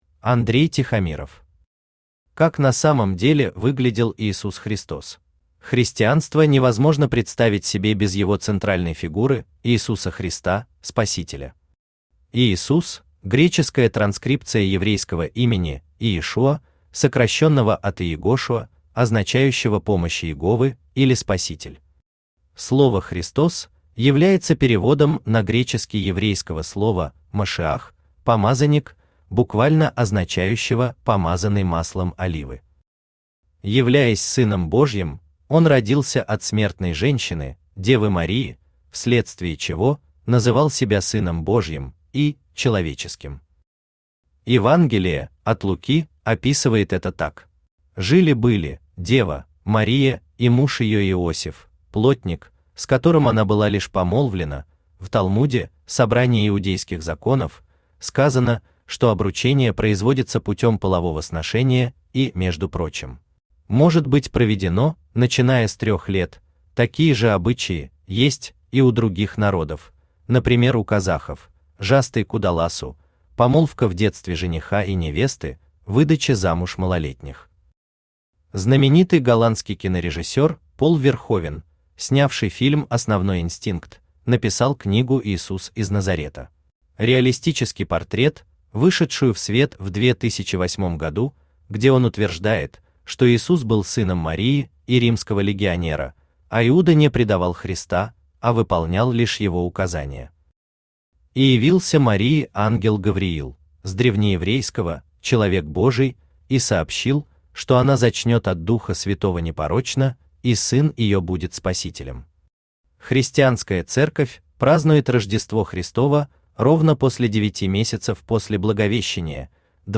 Автор Андрей Тихомиров Читает аудиокнигу Авточтец ЛитРес.